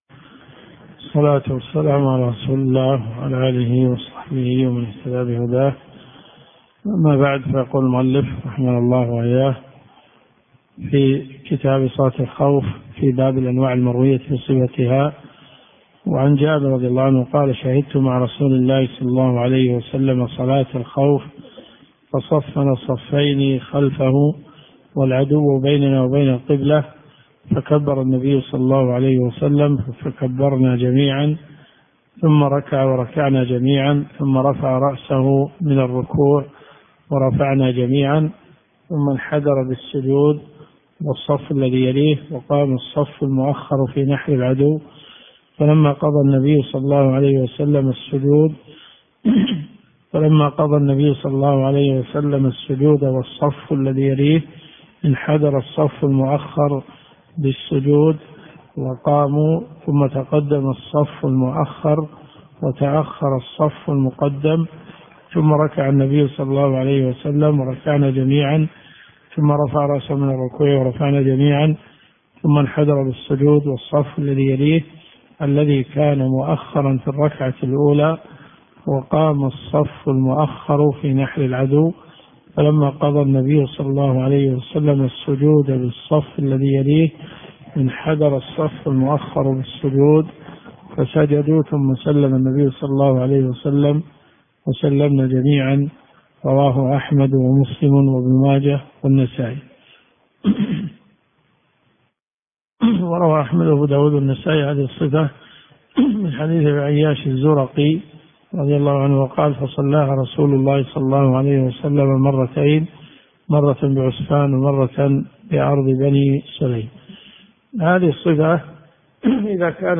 الرئيسية الكتب المسموعة [ قسم أحاديث في الفقه ] > المنتقى من أخبار المصطفى .